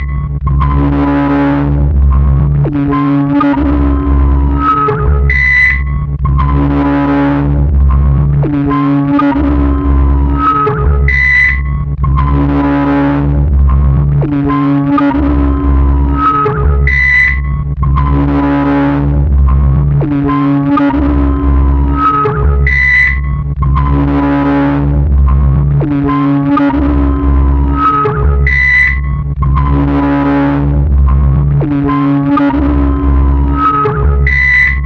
反馈的卷对卷磁带循环录音 " Wiggle Bleeps
描述：失真反馈的口哨声和尖叫声记录在1/4" 磁带上，并进行物理循环
Tag: 失真 反馈 带环 哨子